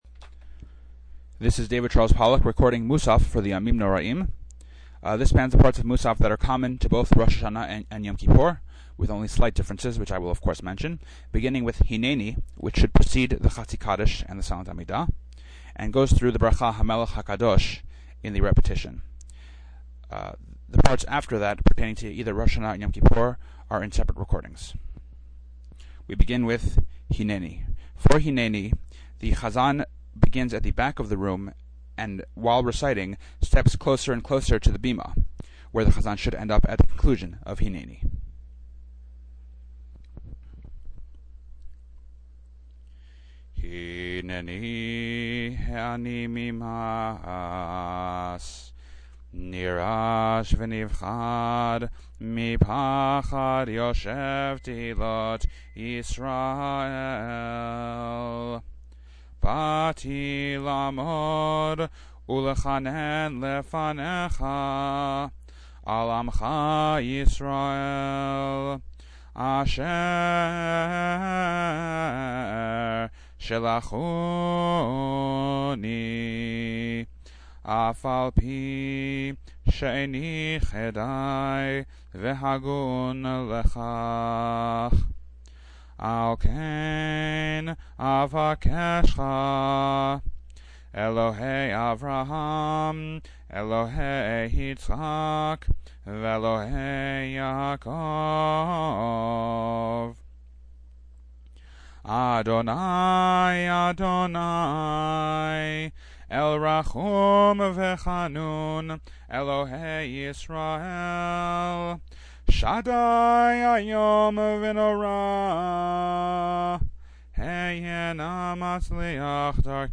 These are various recordings of high holiday services as recorded by me
hh_musaf_common_1.mp3